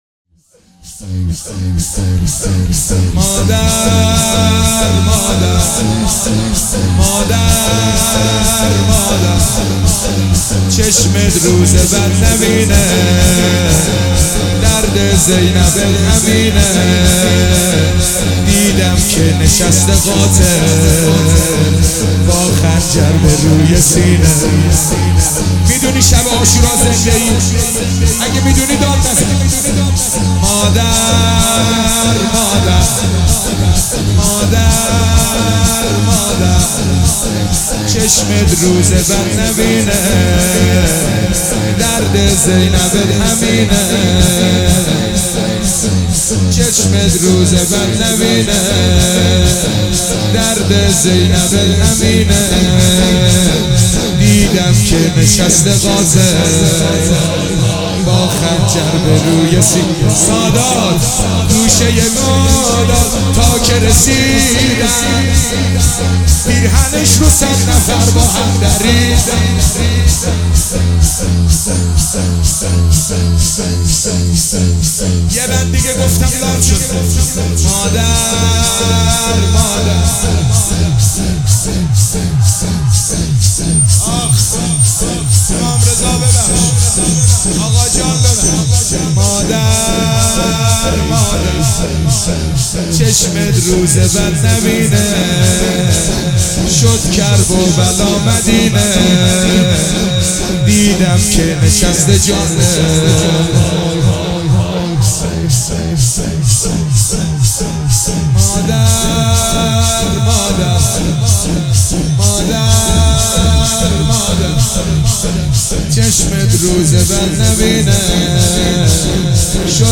دانلود مداحی جدید